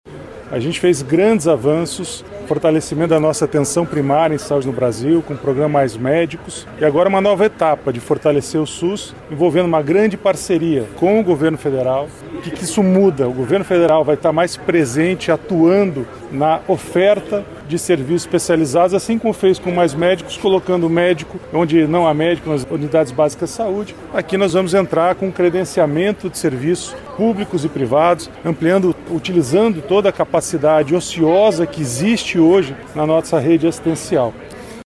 O secretário executivo do Ministério da Saúde, Adriano Massuda, disse que o programa vai dar suporte aos estados e municípios na ampliação de cuidados especializados.